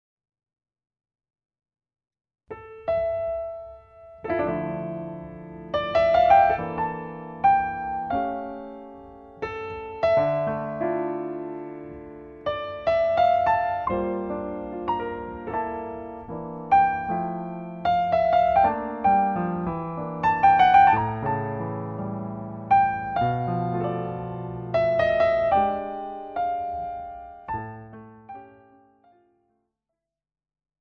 長崎県大島町のホールで録音した心暖まるピアノソロです。